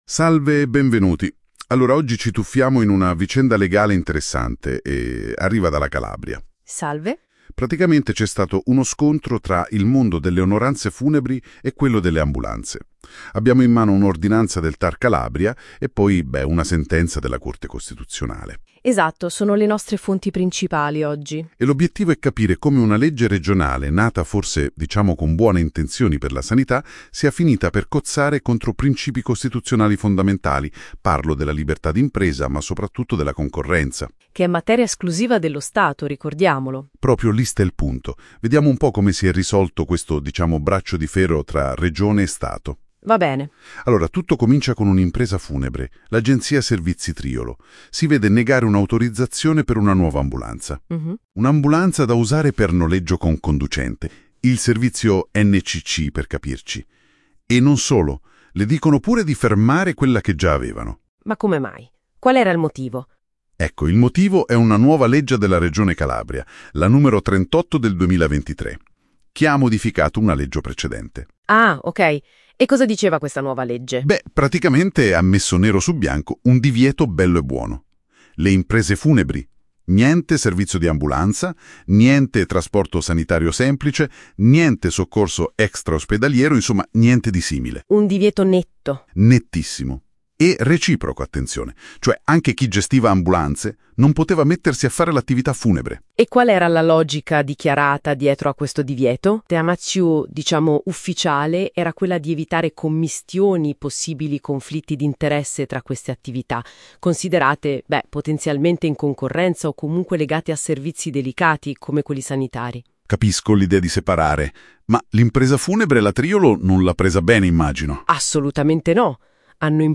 Discussione su sentenza Corte Costituzionale 62/2025